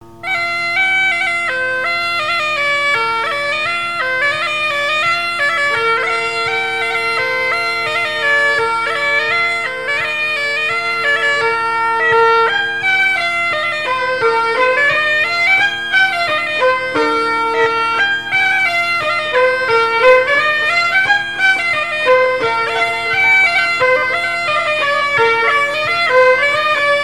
Air n° 2 par Sonneurs de veuze
Airs joués à la veuze et au violon et deux grands'danses à Payré, en Bois-de-Céné
Pièce musicale inédite